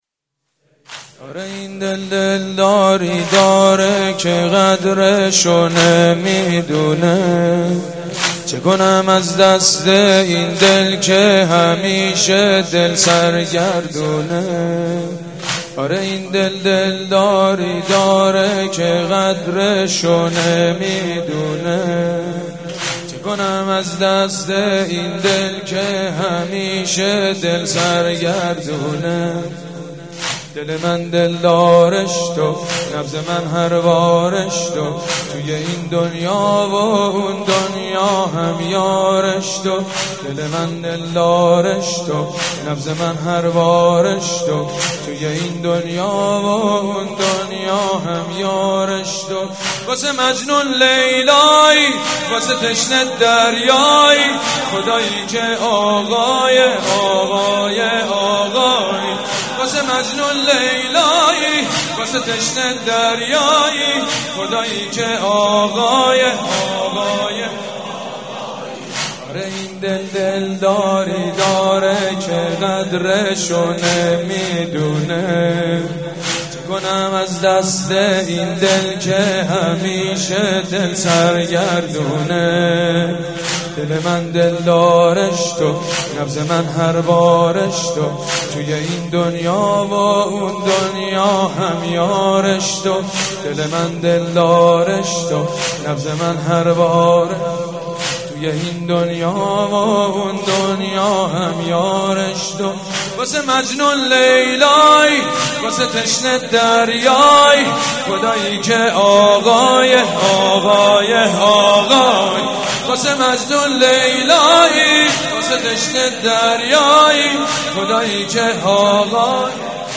شب سوم محرم الحرام 1394 | هیات ریحانه الحسین | حاج سید مجید بنی فاطمه
آره این دل دلداری داره | واحد | حضرت امام حسین علیه السلام